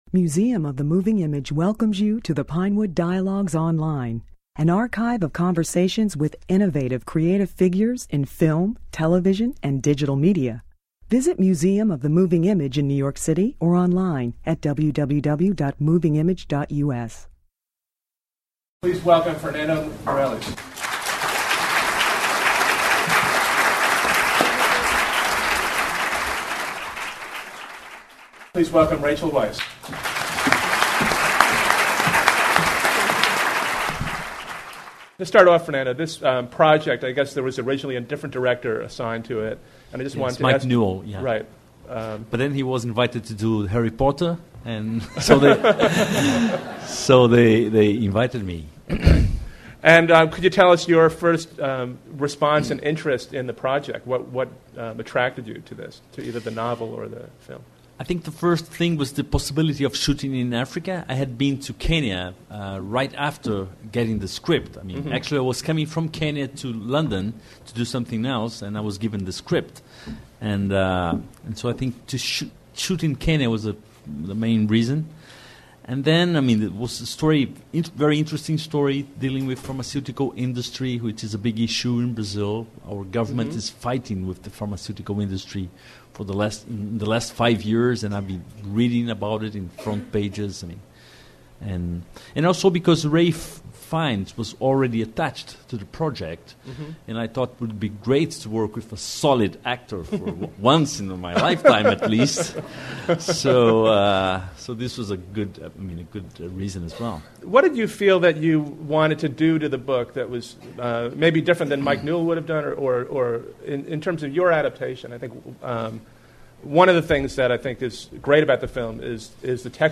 Meirelles and Weisz discuss the film's production and the place of socially relevant films in today's cultural climate.